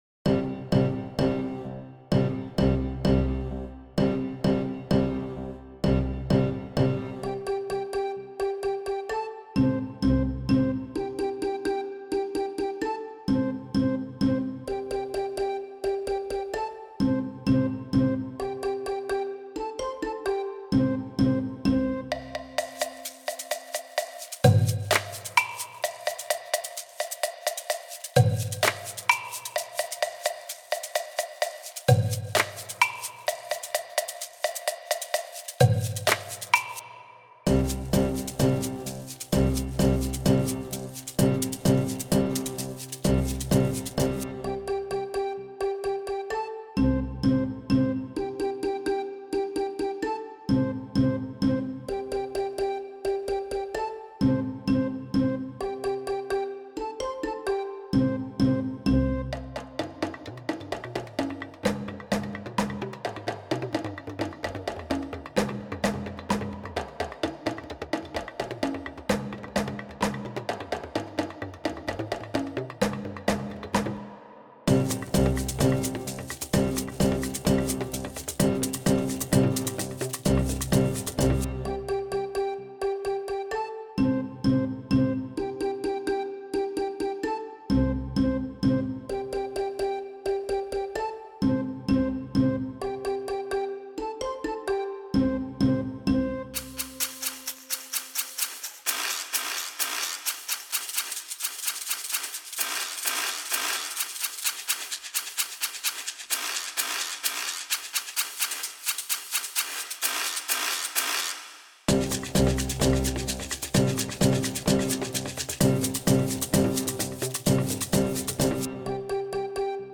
This lively song from Tanzania provides a rhythmic framework for joining in with body percussion, percussion, strings and wind.
This has a semi-spoken chant rhythm (‘Chum Chum Pah’) and the words ‘karilengay kalengana’ about the strength of the lion being in its tail. They use a five-note pentatonic scale, using C, D, E, G, A, and a low A.
The accompaniment features especially the notes C and G on marimba in the ‘Chum Chum Pahs’.